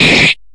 Slash1.ogg